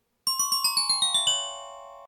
Gliding_gull.ogg